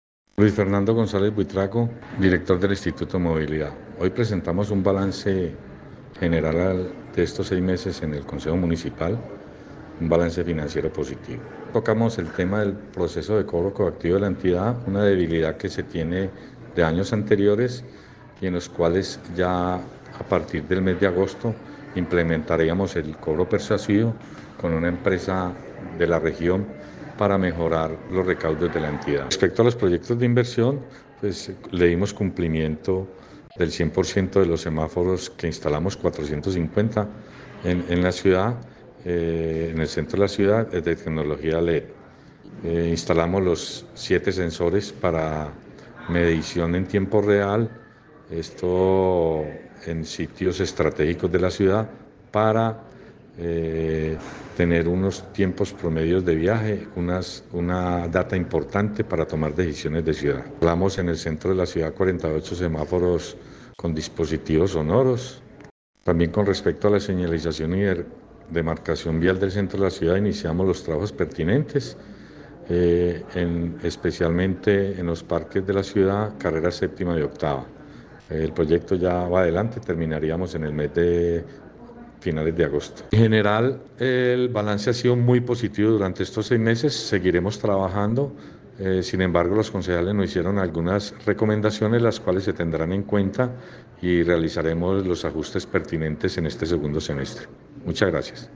Audio-Boletin-16-7-24-Luis-Fernando-Gonzalez-Director-Movilidad.mp3